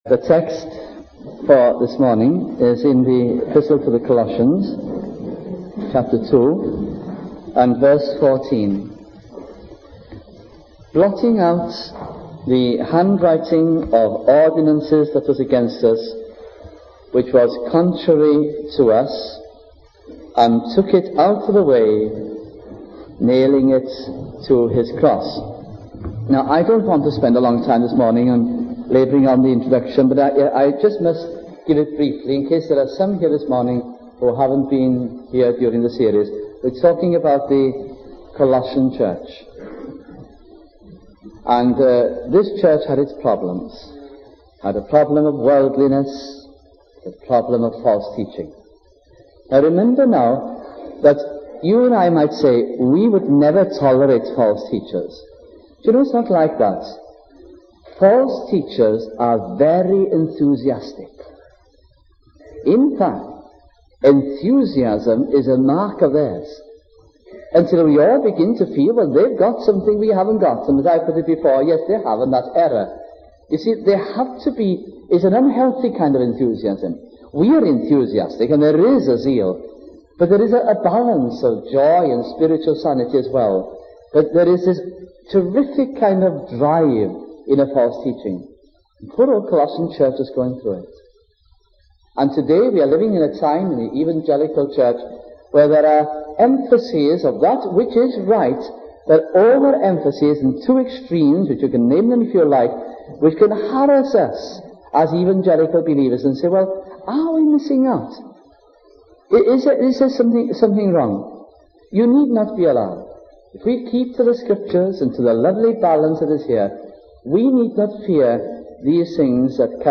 » Colossians Series 1973 » Please note that due to missing parts of the historic audit of recordings this series is incomplete » We also regret that a few sermons in this series do not meet the Trust's expectations of the best sound quality.